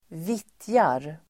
Ladda ner uttalet
Uttal: [²v'it:jar]
vittjar.mp3